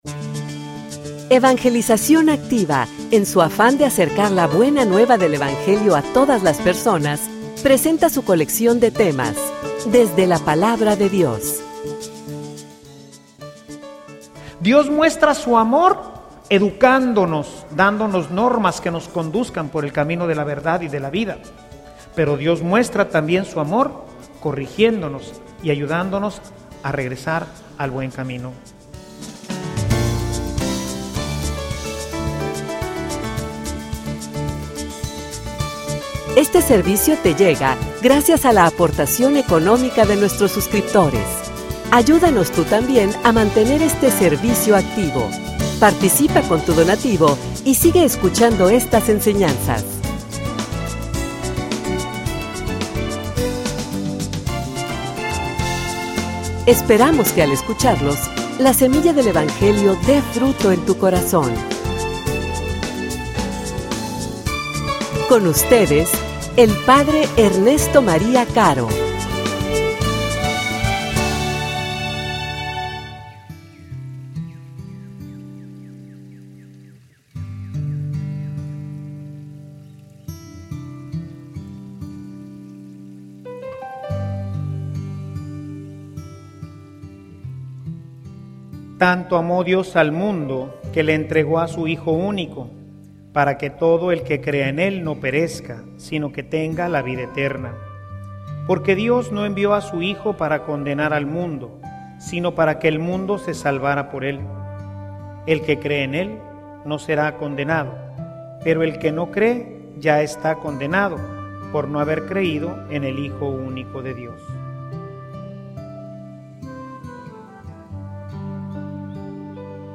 homilia_Un_amor_que_se_expresa.mp3